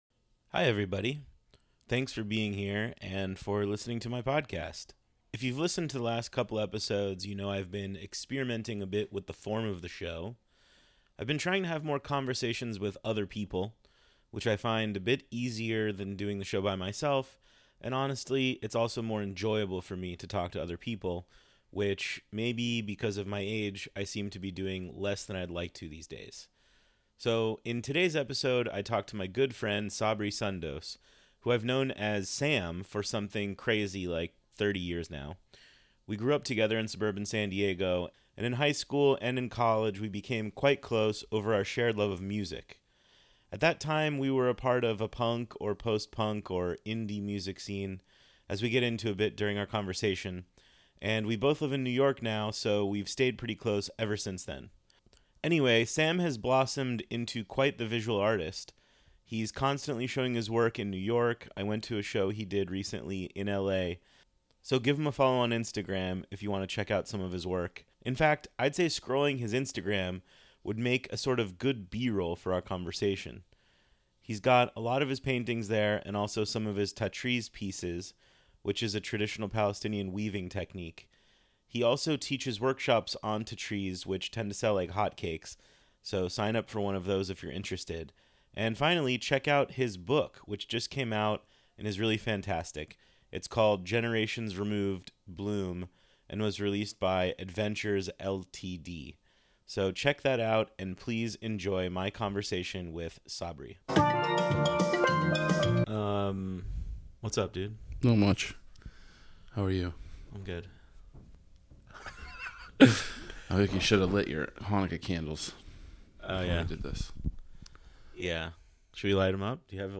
Be a guest on this podcast Language: en Genres: News , News Commentary , Politics Contact email: Get it Feed URL: Get it iTunes ID: Get it Get all podcast data Listen Now...